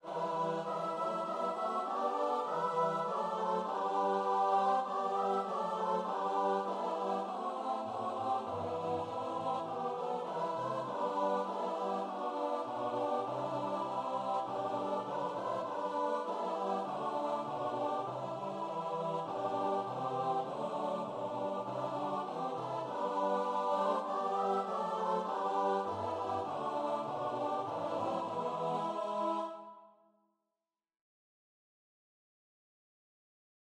Free Sheet music for Choir (SATB)
4/4 (View more 4/4 Music)
Choir  (View more Intermediate Choir Music)